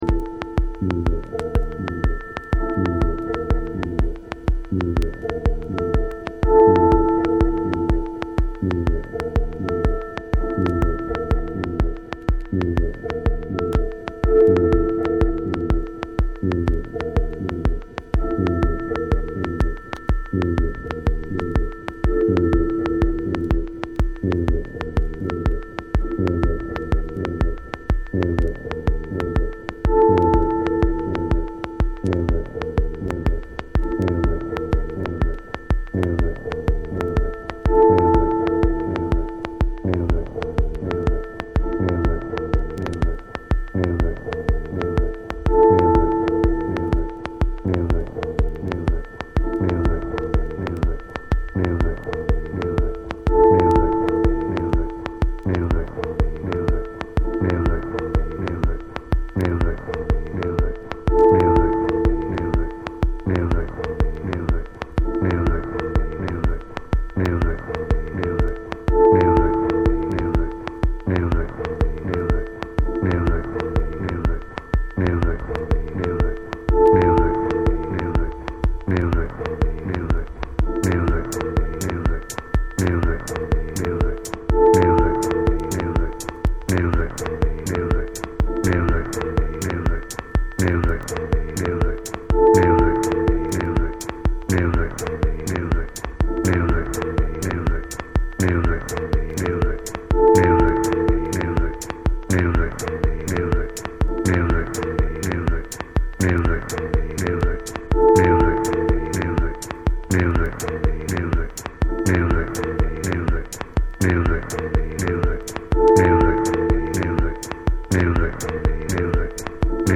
on funky minimal groove.
atmospherical vibes